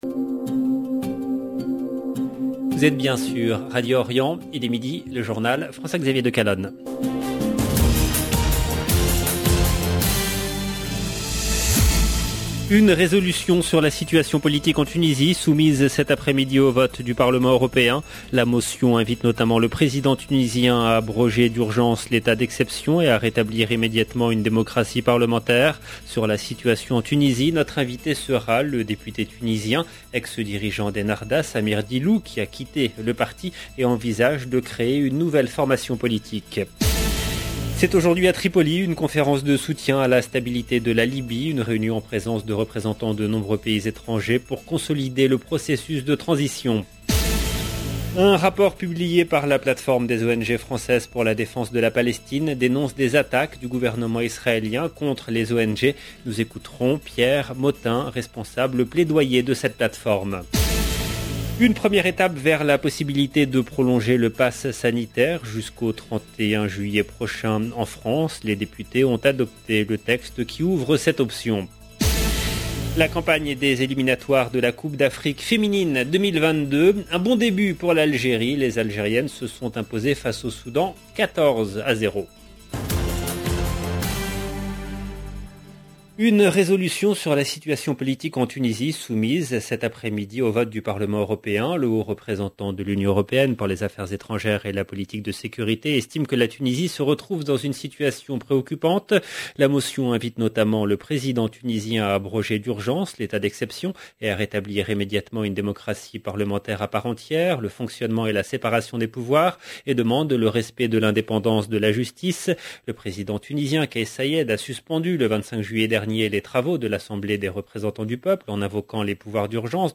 LE JOURNAL DE MIDI EN LANGUE FRANCAISE DU 21/10/21